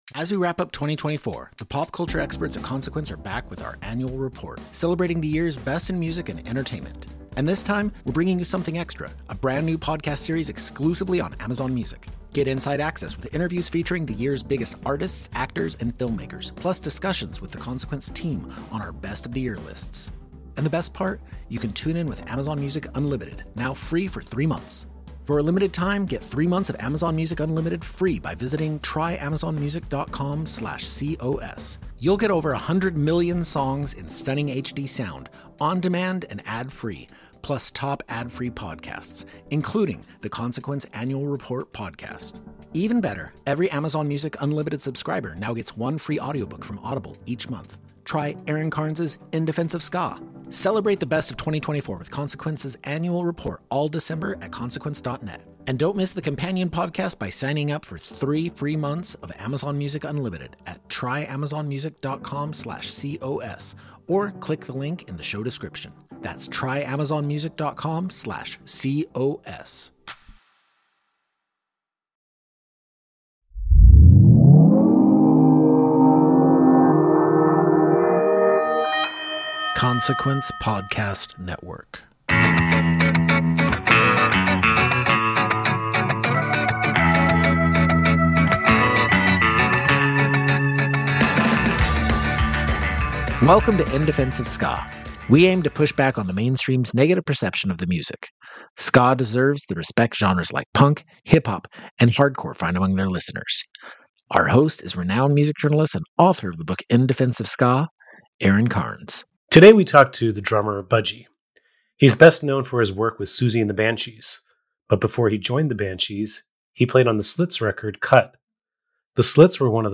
This week on In Defense of Ska, the podcast welcomes Siouxsie and the Banshees drummer Budgie, who also worked on Cut with The Slits, to talk about revolutionary record and more.